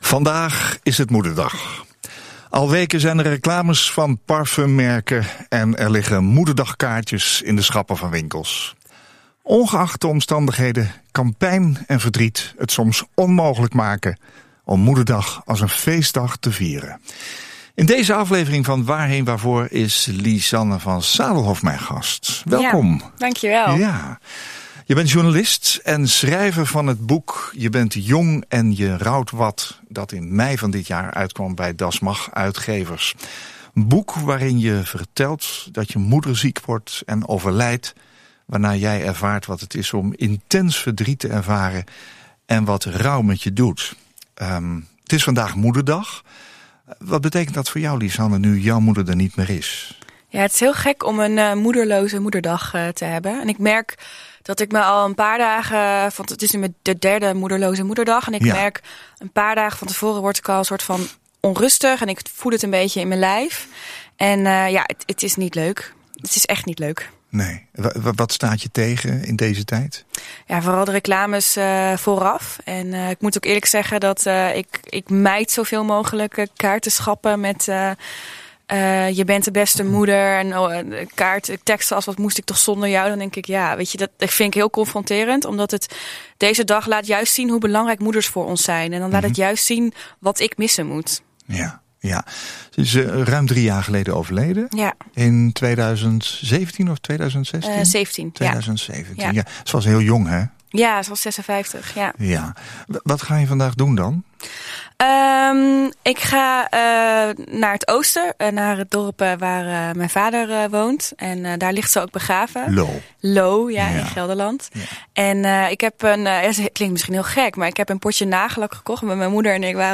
praat met een gast over leven en dood, met een glimlach.